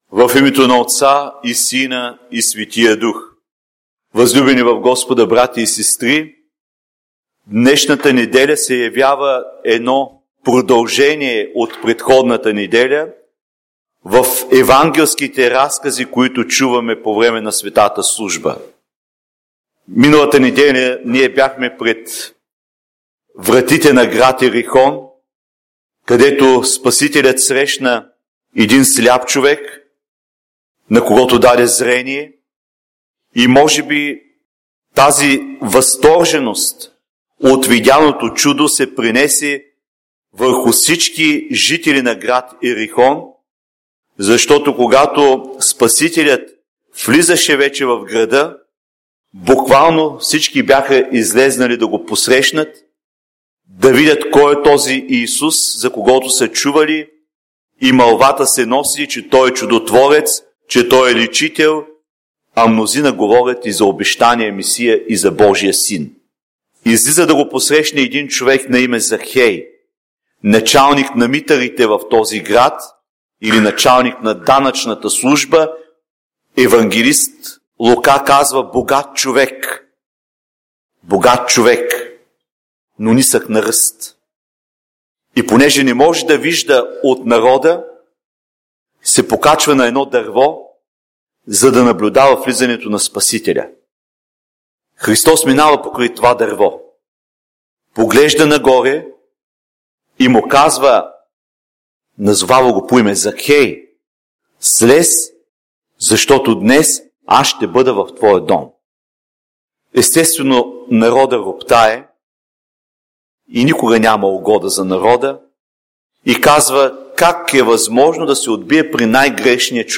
Неделя 15 след Неделя подир Въздвижение - на Закхея - Проповед - Храм "Св.